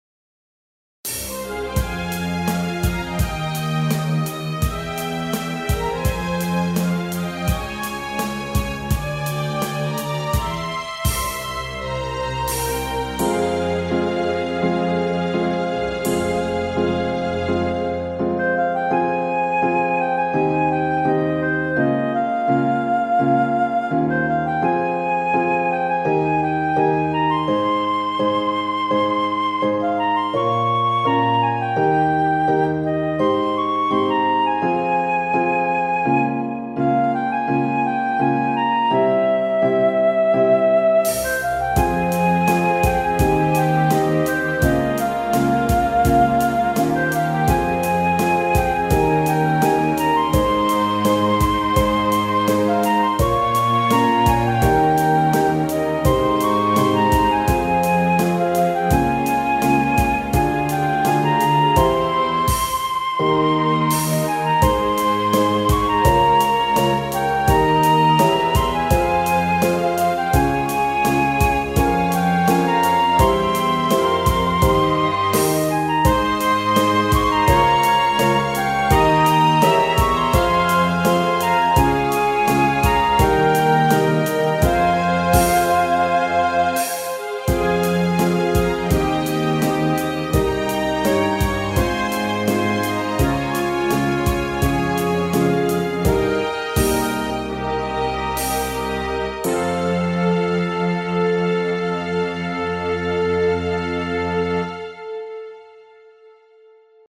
クラシックショート暗い